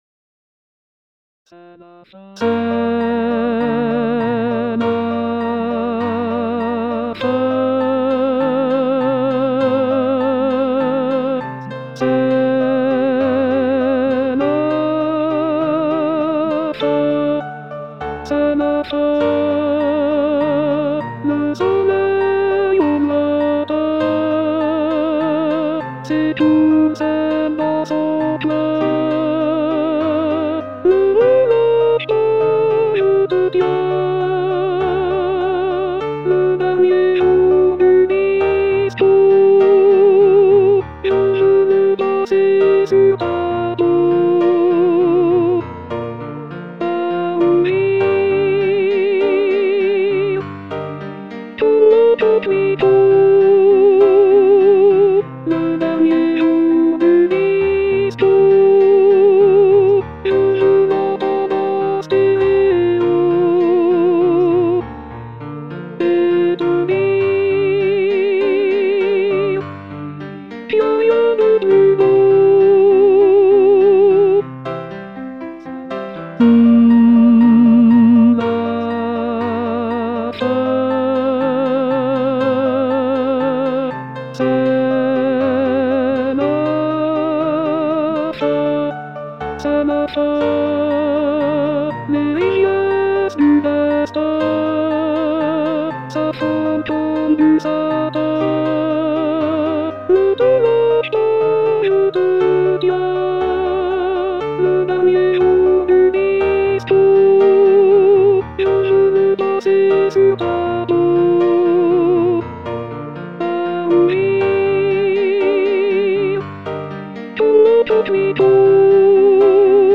voix chantée IA